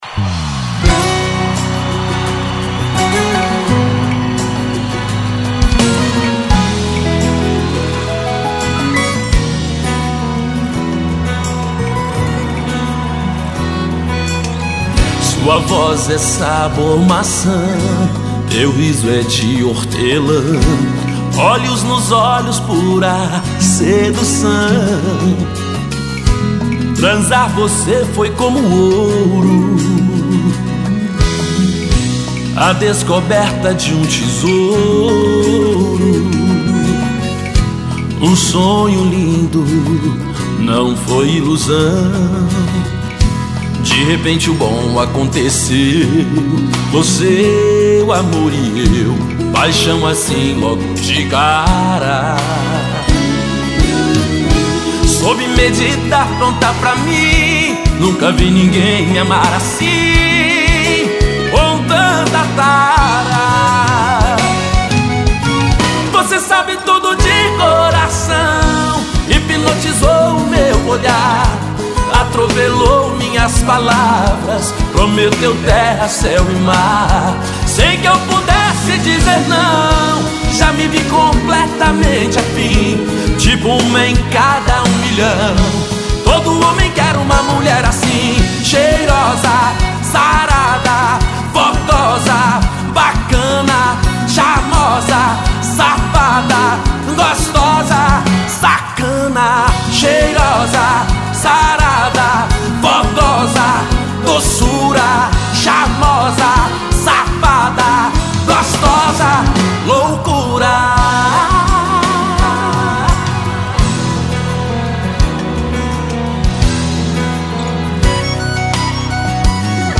VOCALISTA
DUPLA